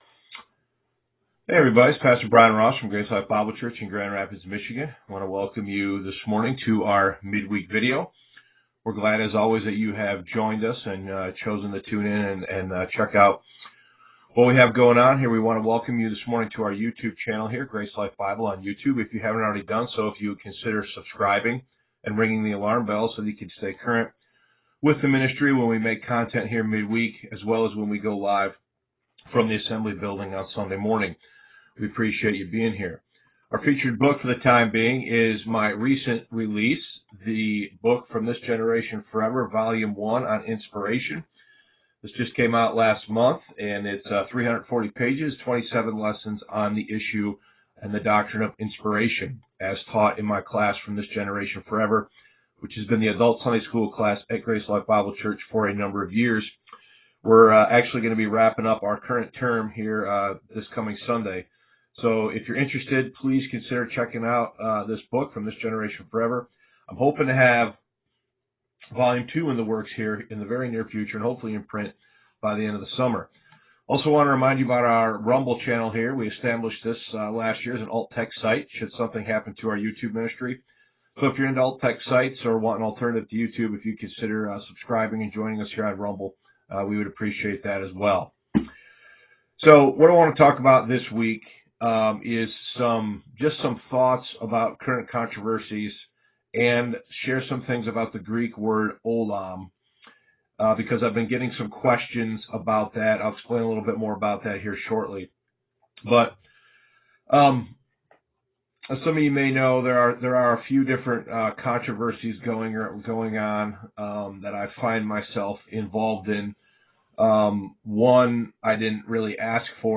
Facebook Live Videos (Vlogs) , Mid-Week Messages